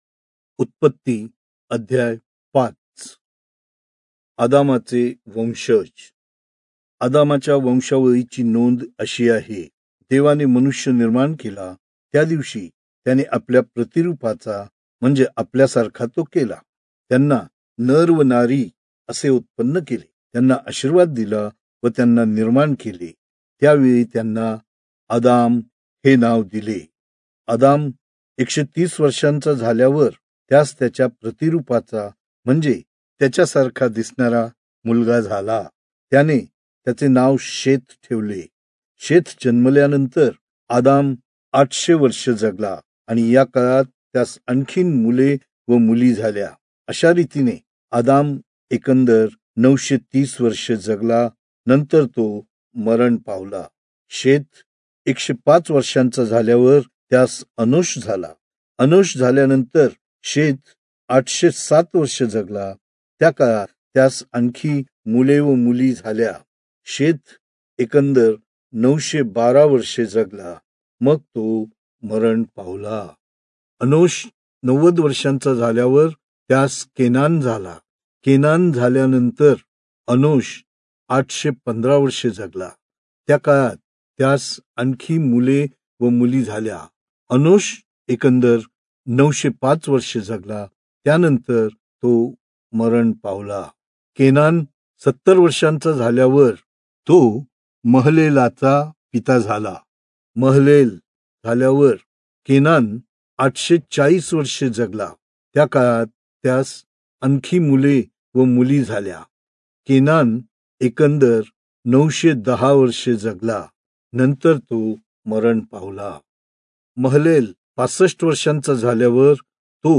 Marathi Audio Bible - Genesis 40 in Irvmr bible version